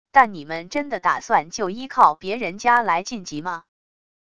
但你们真的打算就依靠别人家来晋级吗wav音频生成系统WAV Audio Player